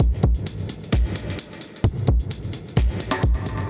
Index of /musicradar/dub-designer-samples/130bpm/Beats
DD_BeatD_130-03.wav